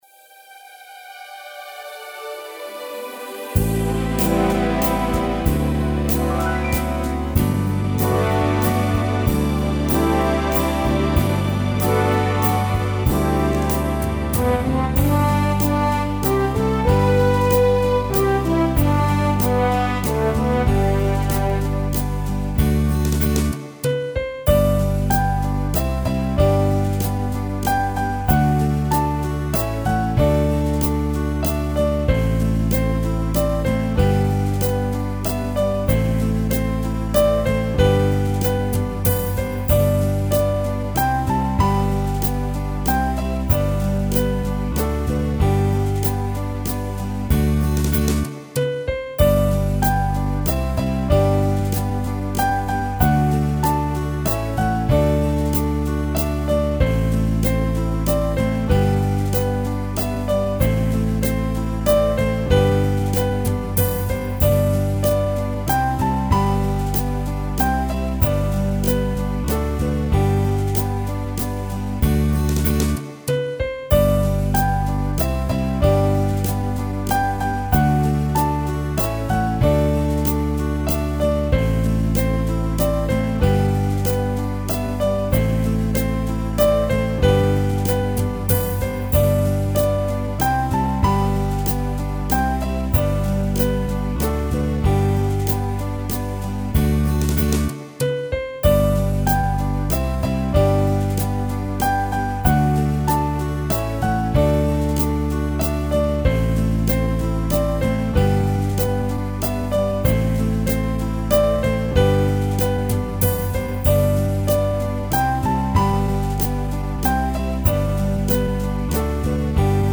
Der spilles 4 vers:
Der spilles lidt forspil
Start med at synge efter 23 sekunder